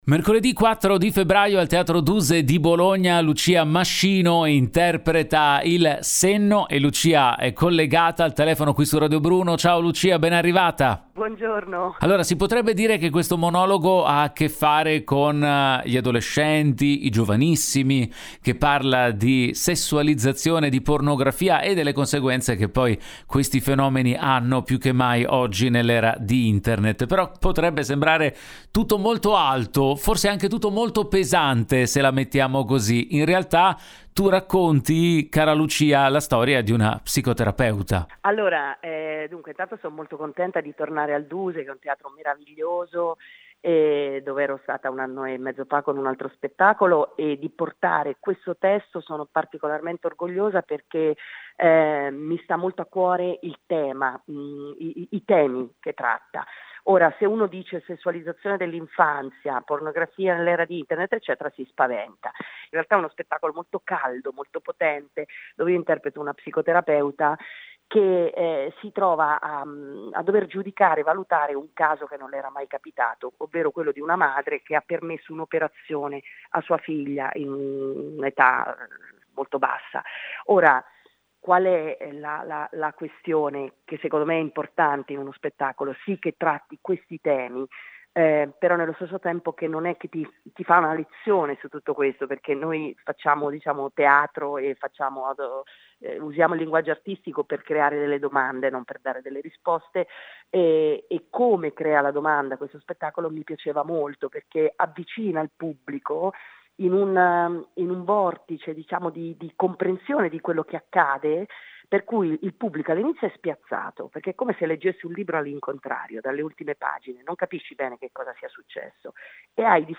Sentiamo l’attrice al microfono